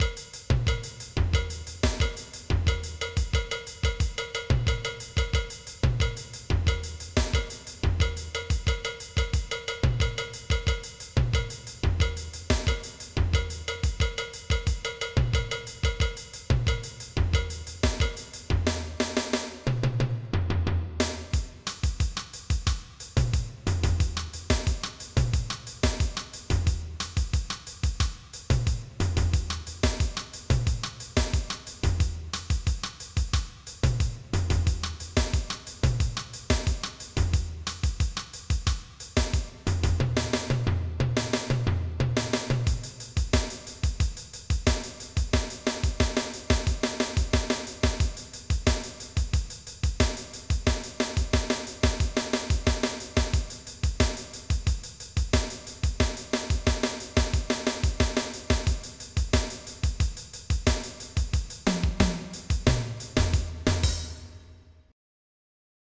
MIDI Music File
samba.mp3